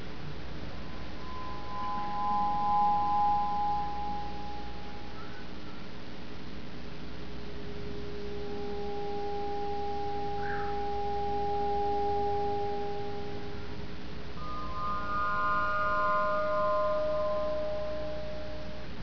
My Aeolian Harp with the lid off
Aeolian harps are ancient stringed instruments that are played by the wind.
Aeolian harps are most often built to fit on one's windowsill; a strong breeze generates lovely, ethereal harmonics as it vibrates the strings. Like wind chimes, the music is random. Unlike wind chimes, the pitch combinations fade in and out unpredictably. Sometimes one note will hang in the air for several seconds, then be joined by a chorus.
This tuning eliminates dissonance, as the vibrations produce only one harmonic series with nothing else to clash.
aeol8bit.wav